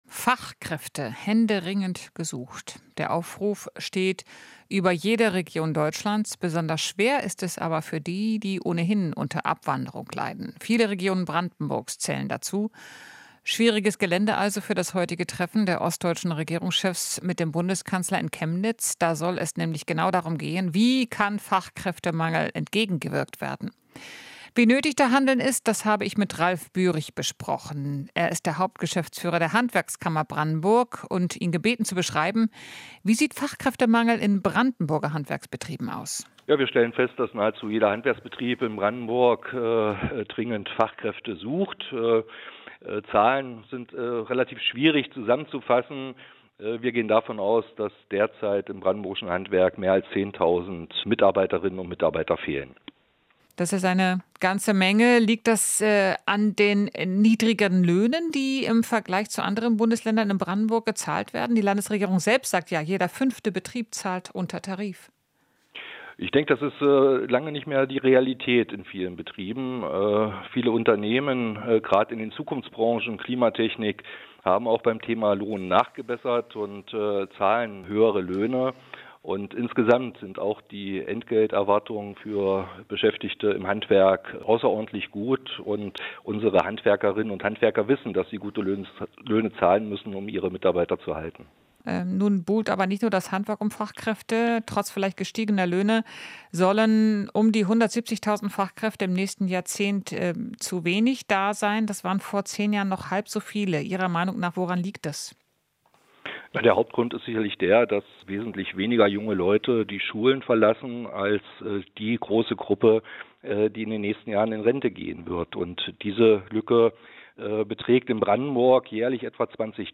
Interview - Handwerkskammer kritisiert Bürokratie bei Zuwanderung